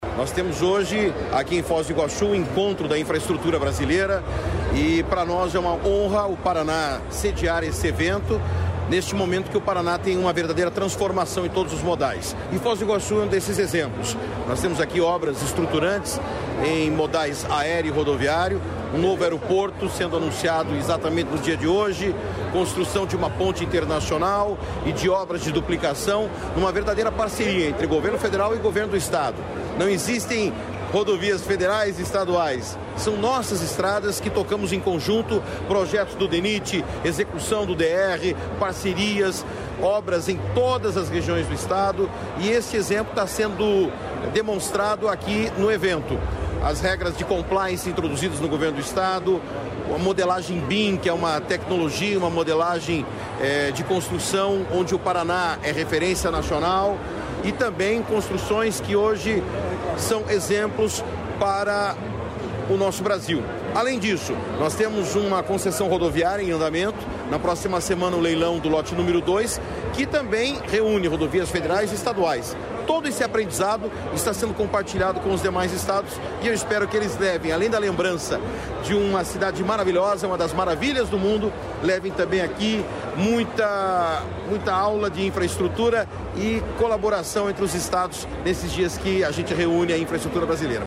Sonora do secretário de Infraestrutura e Logística, Sandro Alex, sobre o 25° Enacor